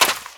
STEPS Sand, Run 23.wav